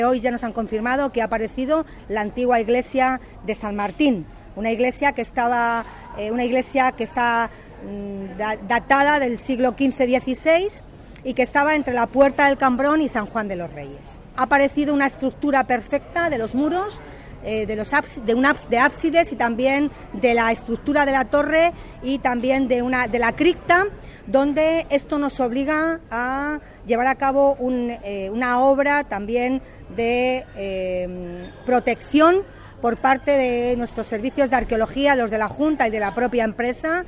Una iglesia que según los planos de aquella época se encontraba entre la Puerta del Cambrón y el Monasterio San Juan de los Reyes, tal y como ha explicado la alcaldesa a los periodistas durante su visita a las obras de rehabilitación de la calle Toledo Ohio, vía por la que se ha iniciado la intervención de mejora y acondicionamiento del eje Toledo Ohio-Tendillas.